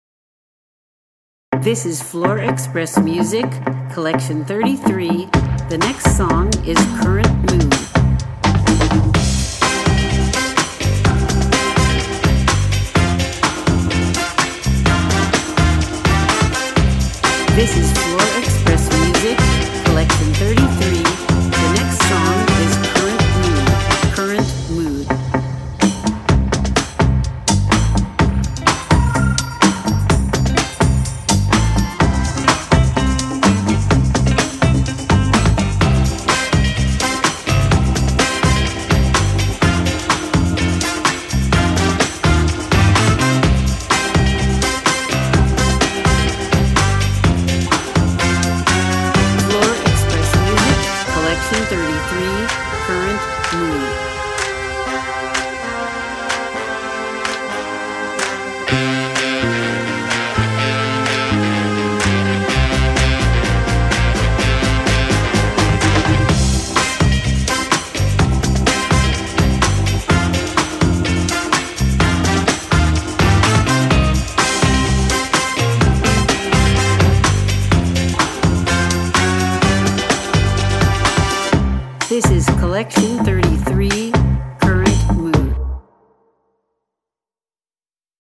• Funk
• Big Band